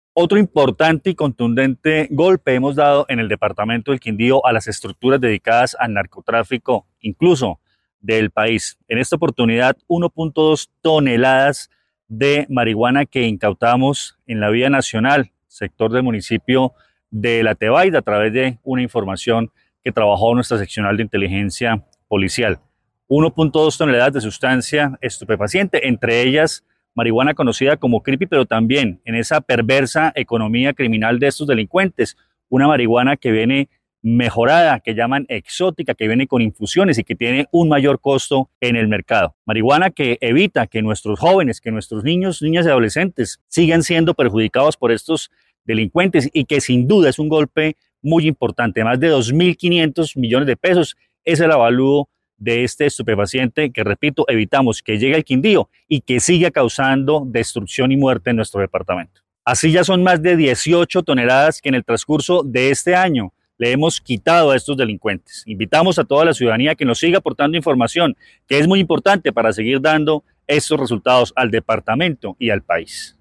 Coronel Luis Fernando Atuesta, comandante de la Policía sobre incautación